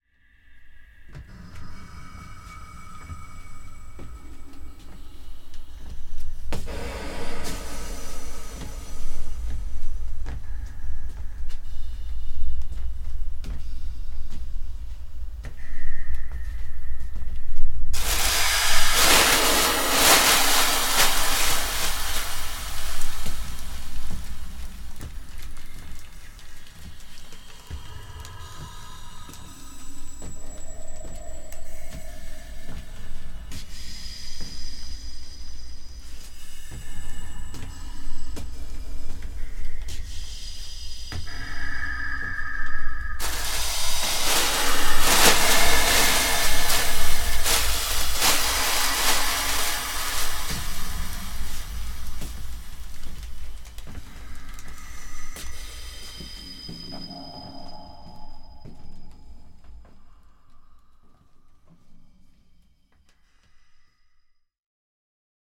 interactive installation for computer, four loudspeakers, one microphone,
metal tubes, cloth sheets, jute bags and other scrap
Passing on a sonorous zone of the floor,